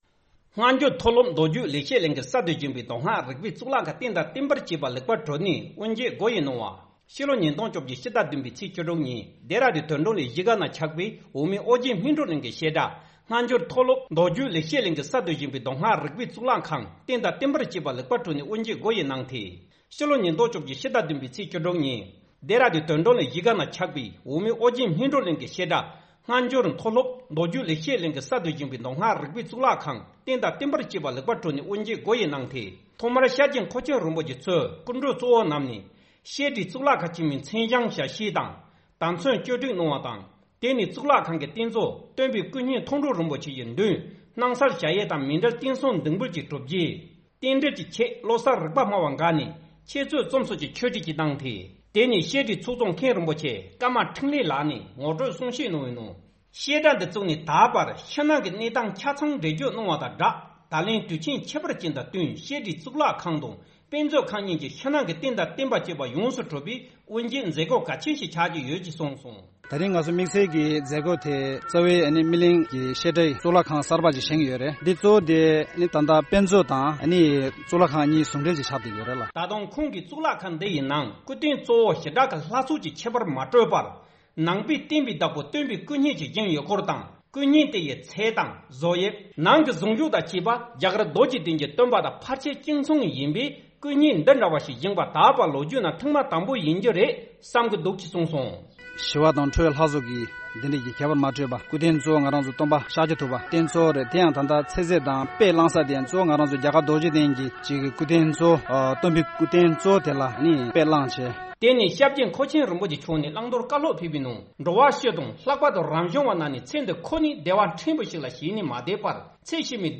ང་ཚོའི་གསར་འགོད་པ